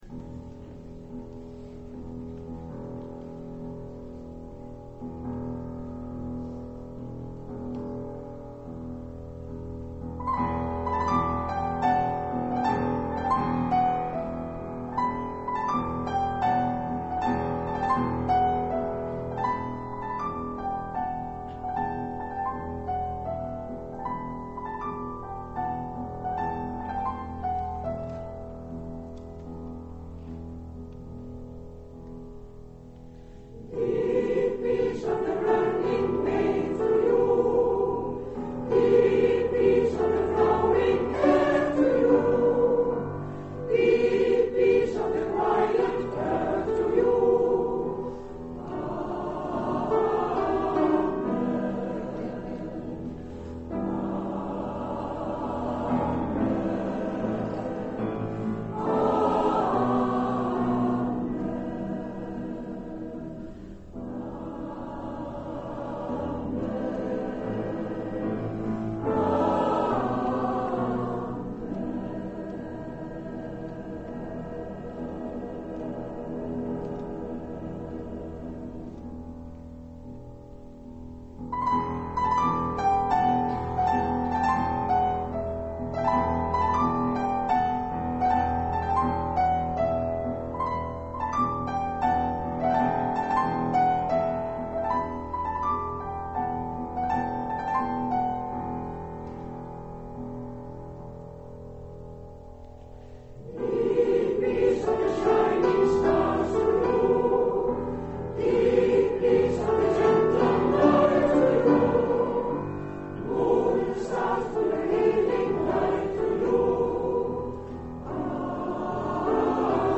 Gemengd Koor d'Eyckelbergh
We zingen een licht klassiek repertoire.